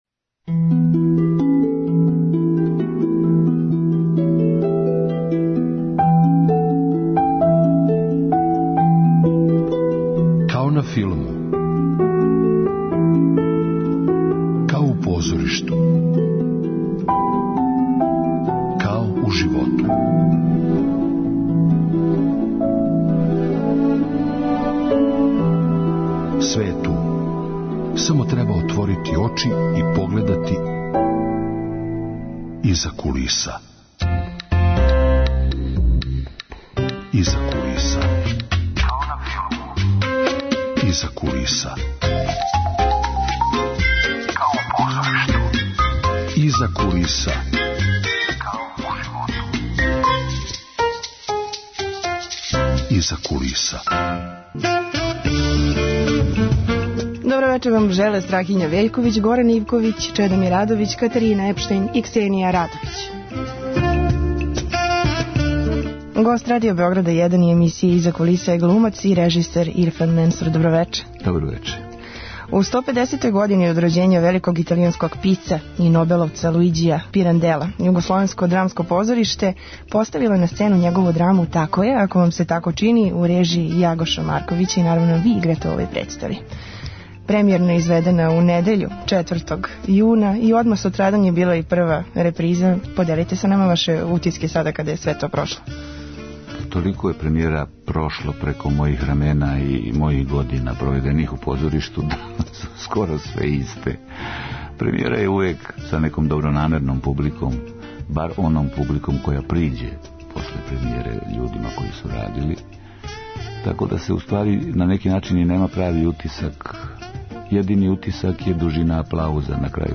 Гост: глумац и редитељ Ирфан Менсур.